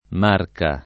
[ m # rka ; fr. mark # ]